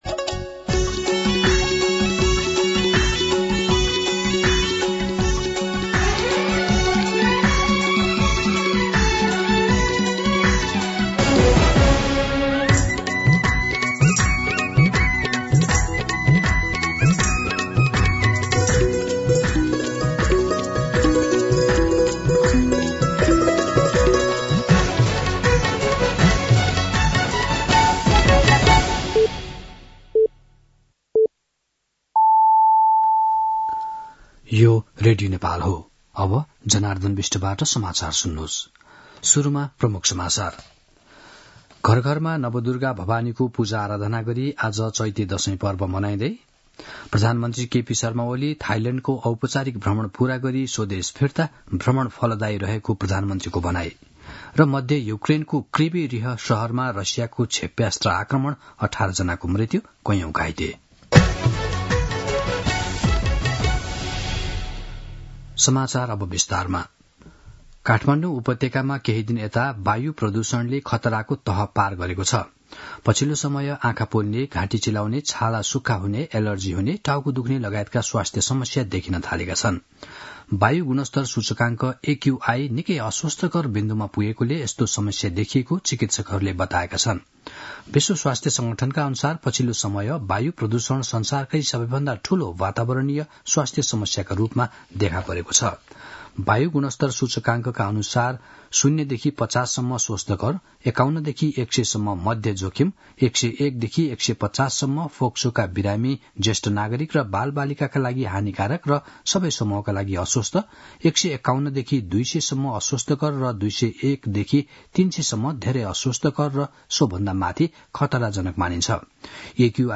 दिउँसो ३ बजेको नेपाली समाचार : २३ चैत , २०८१
3pm-Nepali-News.mp3